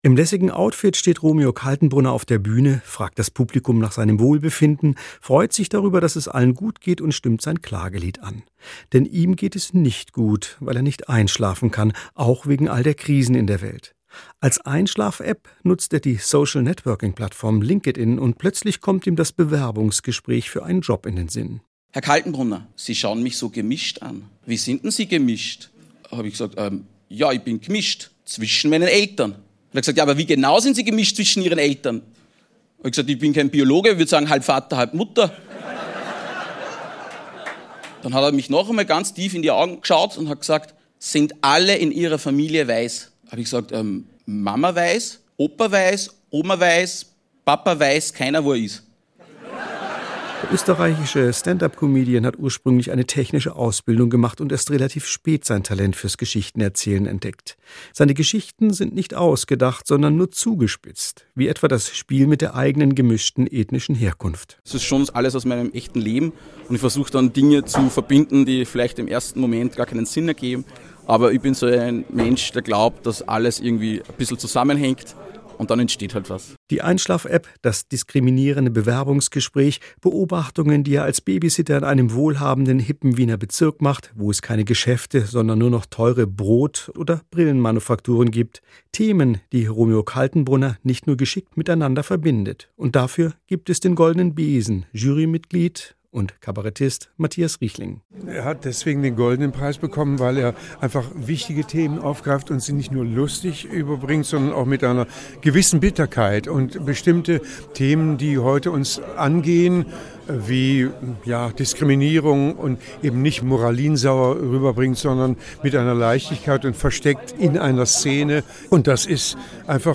Aufnahme vom 10. März 2026 im Renitenztheater Stuttgart